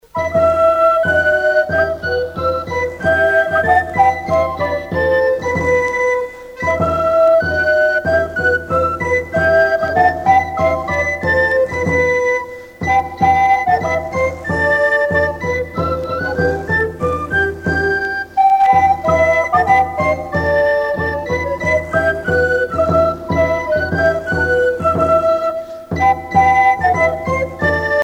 Noël, Nativité
Pièce musicale éditée